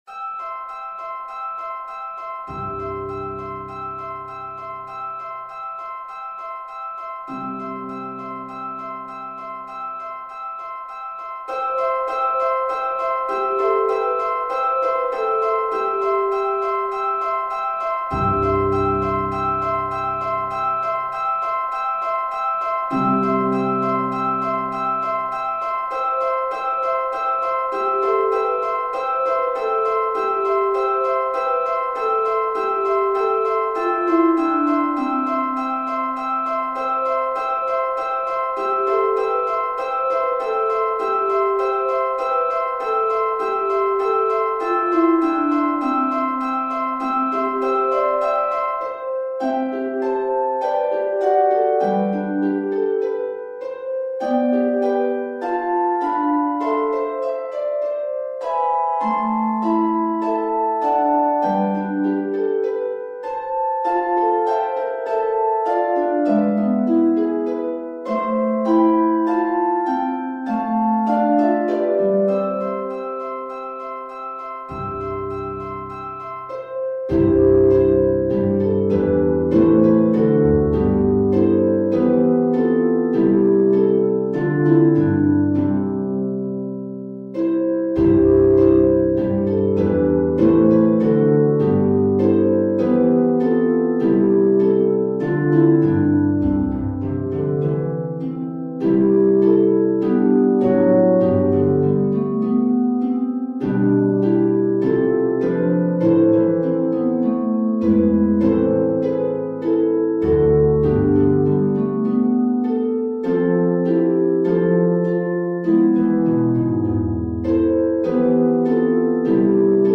Pedal Harp
harp solo